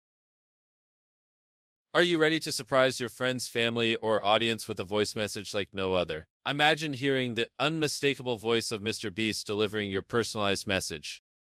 Custom Voice Message with Mr.Beast Voice
2. High-Quality Audio: Receive a professionally crafted audio file that sounds authentic and crystal clear.
3. Voice Creation: Our professional voice artists use state-of-the-art technology to replicate MrBeast’s voice with stunning accuracy.
• Motivational Boosts: Send a friend or colleague an encouraging message in MrBeast’s energetic style.
Custom-Voice-Message-with-MrBeast-Voice.mp3